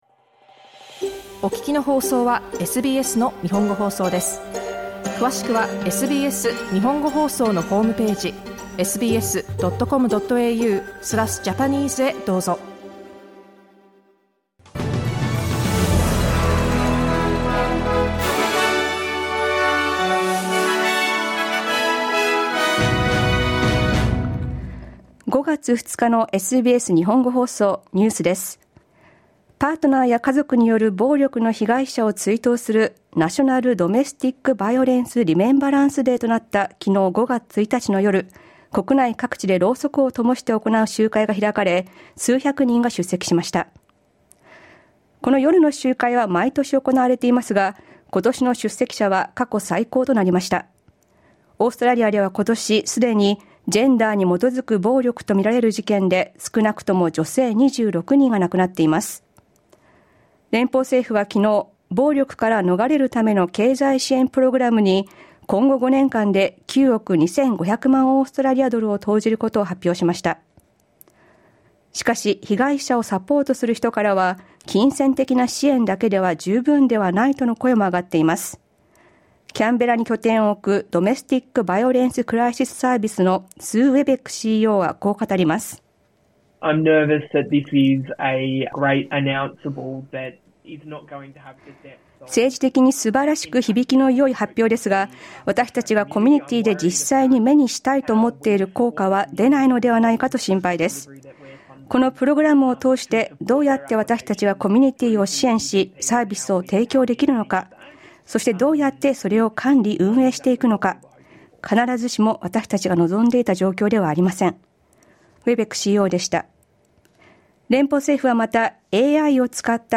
午後１時から放送されたラジオ番組のニュース部分をお届けします。